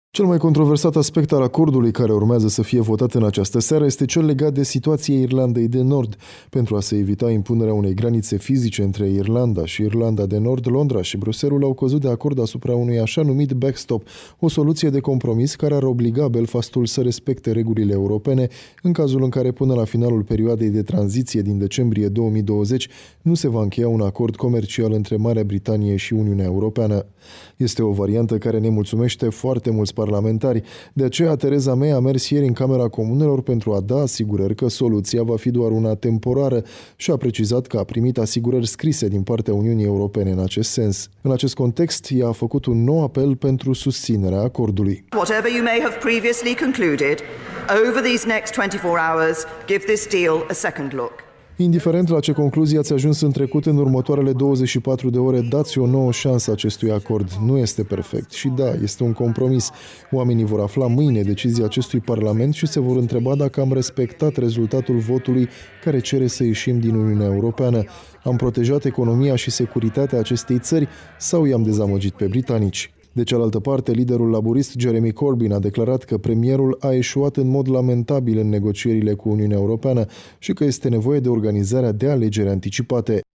O corespondenţă din Londra